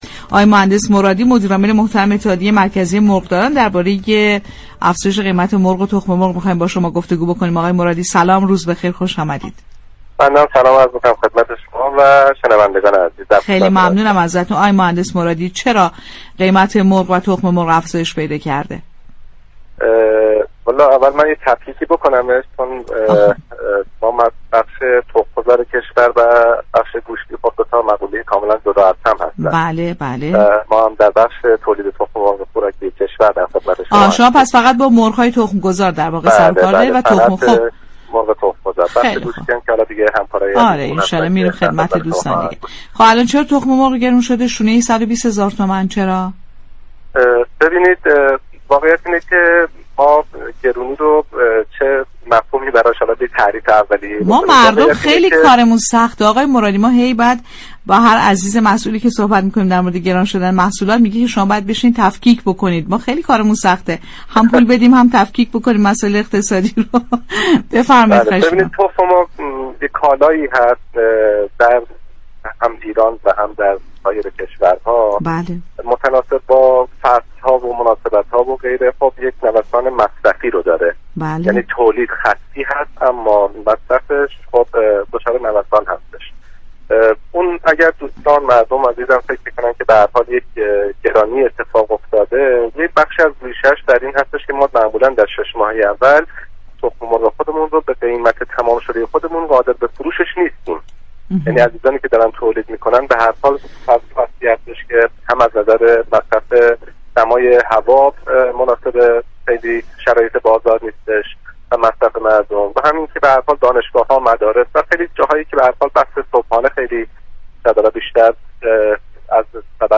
در گفت و گو با «بازار تهران»